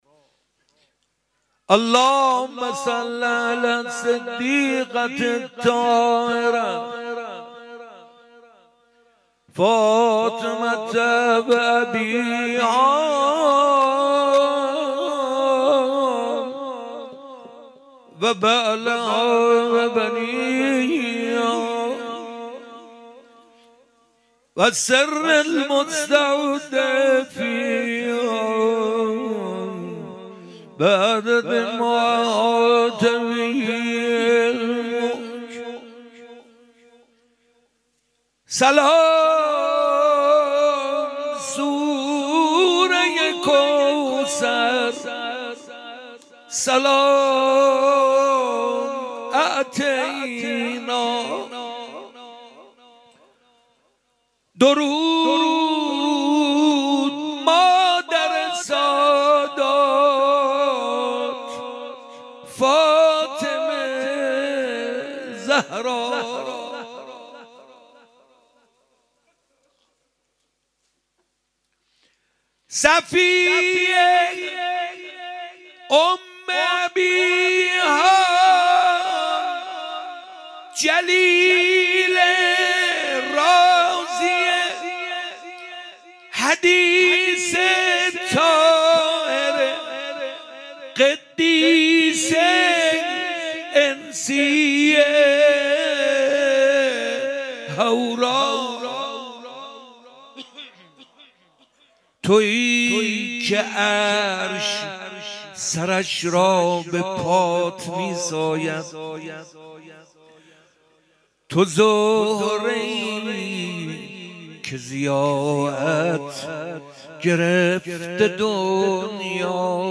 مدح حضرت زهرا سلام الله علیها